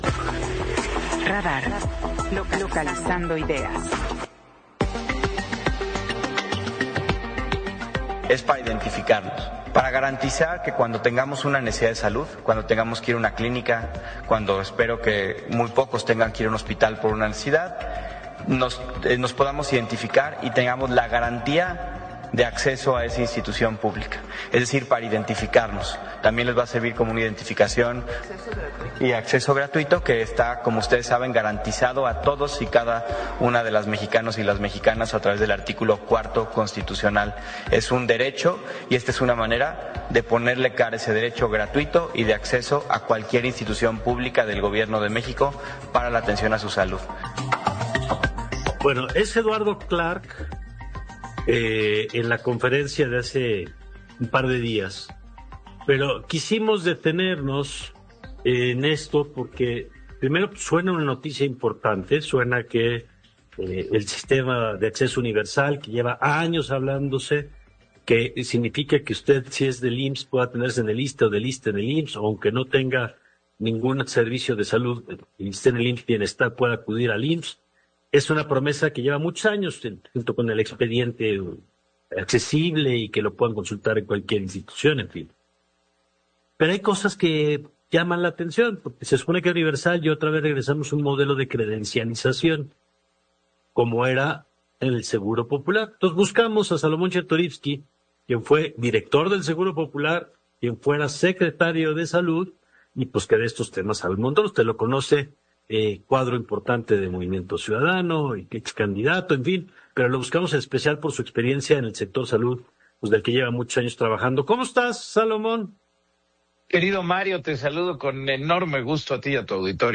El ex director de IMSS explicó en entrevista que, más allá de un acceso universal, debe existir un expediente interinstitucional.